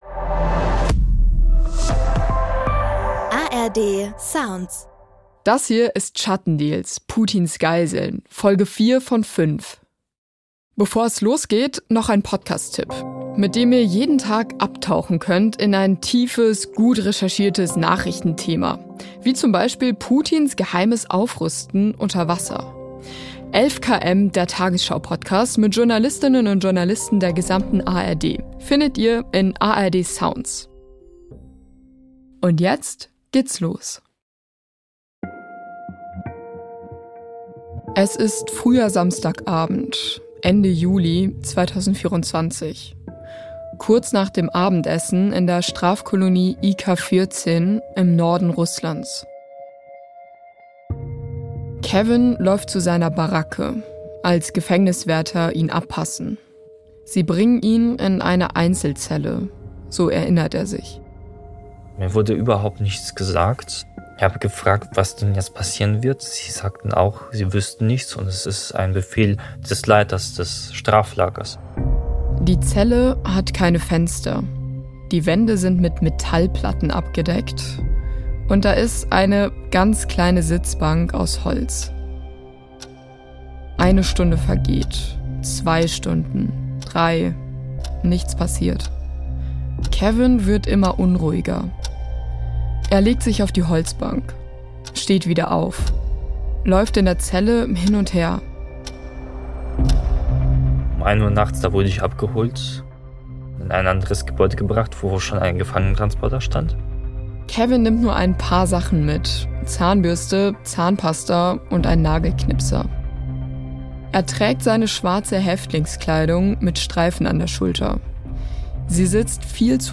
Hinweis zum Sound: In einzelnen Szenen haben wir Hintergrund-Geräusche nachgestellt. Alle Interview-Töne sind real.